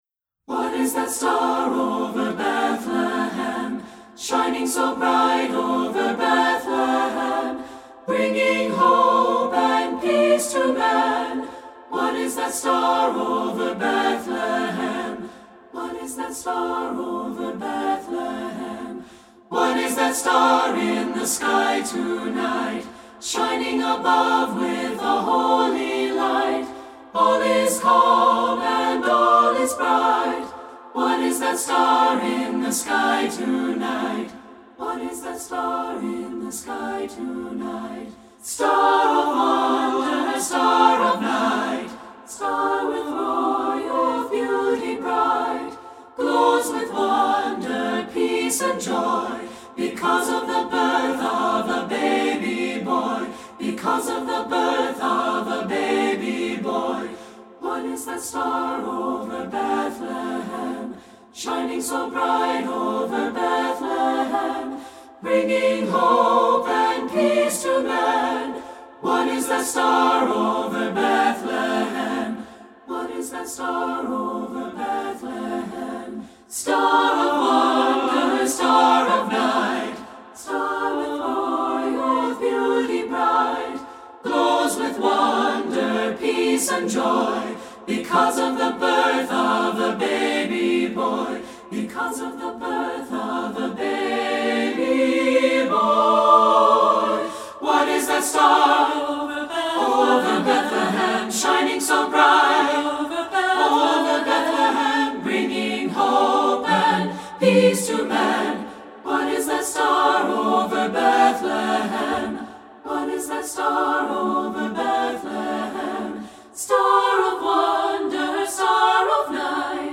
Voicing: 3-Part Mixed a cappella